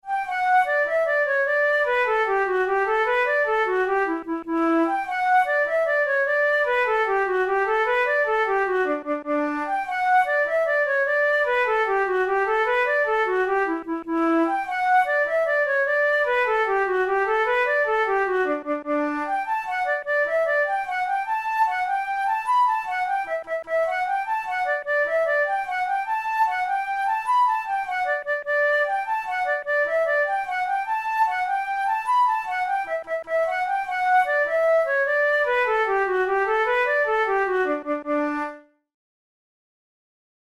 InstrumentationFlute solo
KeyD major
Time signature6/8
Tempo100 BPM
Jigs, Traditional/Folk
Traditional Irish jig